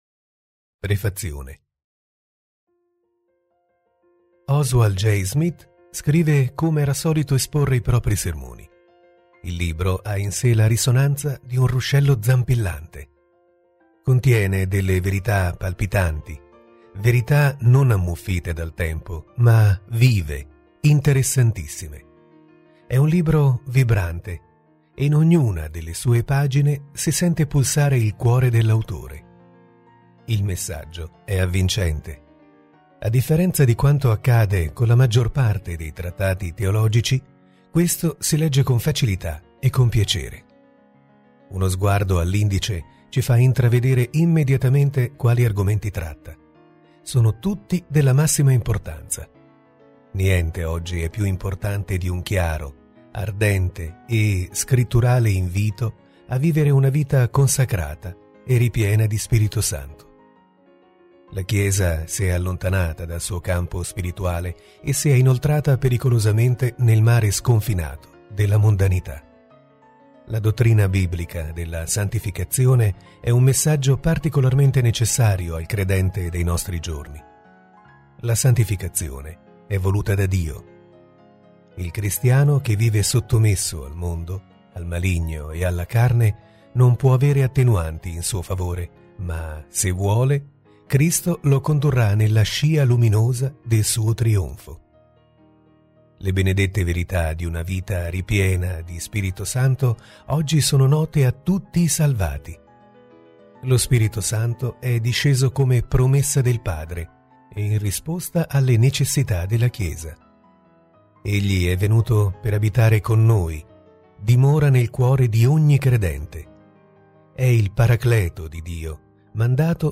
Lettura integrale MP3